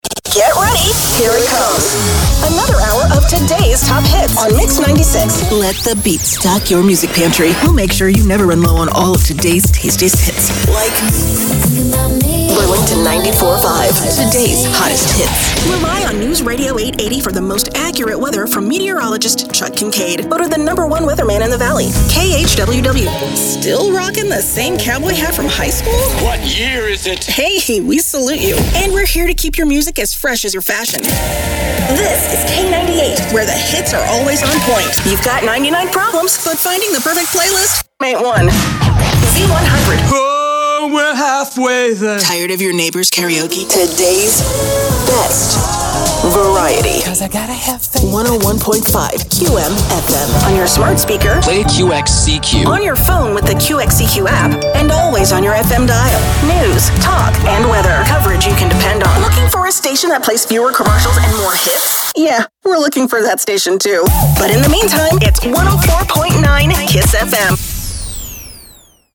My style is quirky and cool.
Commercial